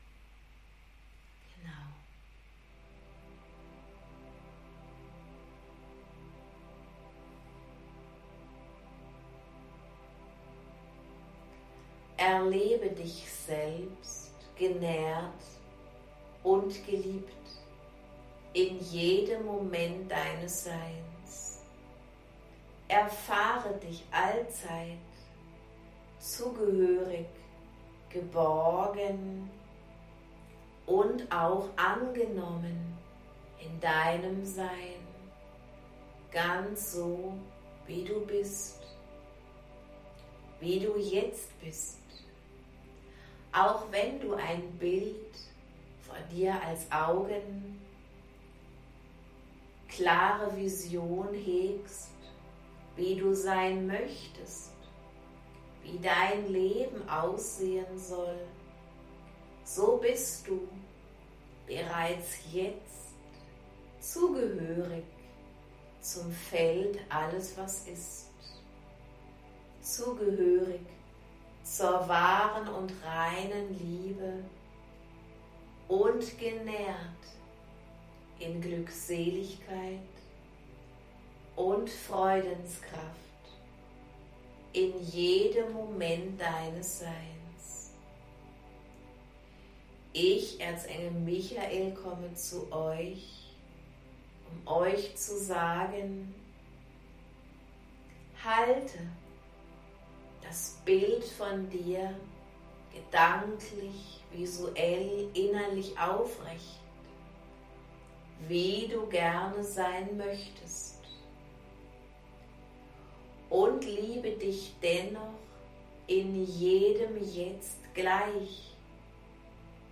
06ErzengelMichaelOeffentlichesChannelingWebHP.mp3